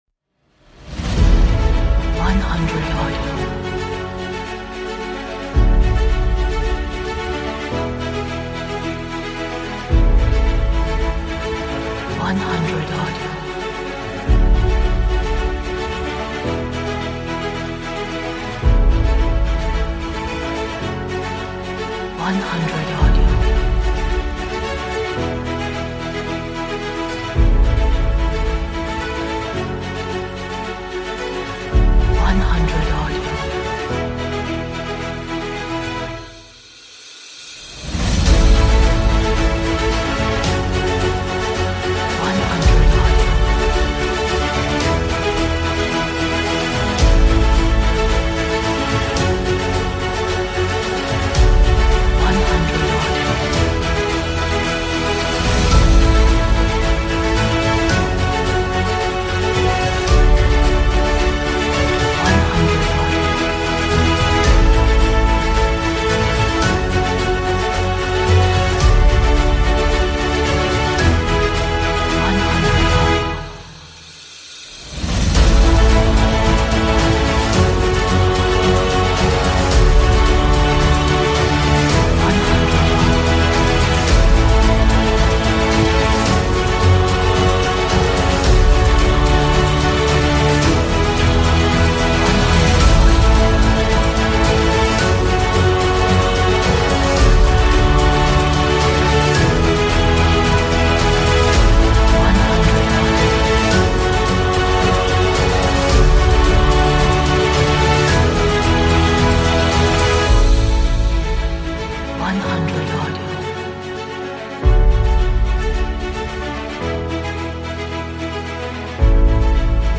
Atmospheric, dramatic, epic, energetic, romantic,